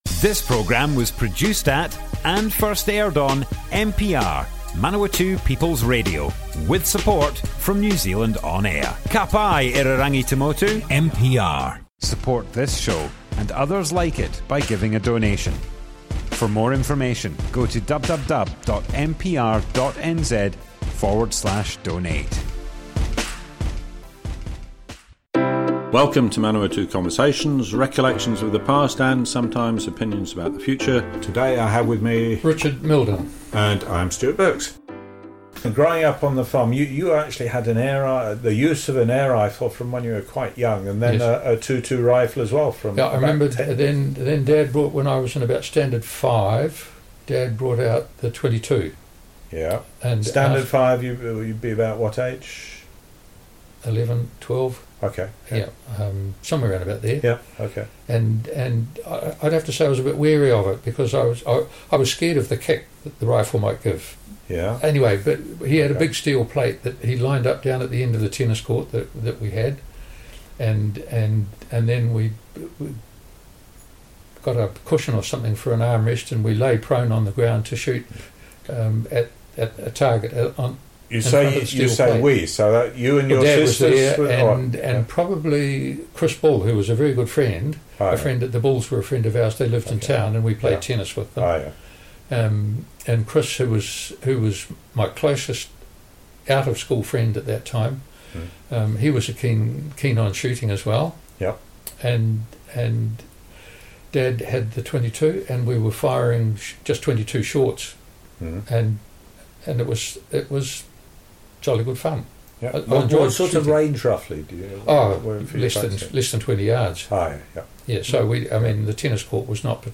Manawatu Conversations More Info → Description Broadcast on Manawatu People's Radio, 21 September 2021.
oral history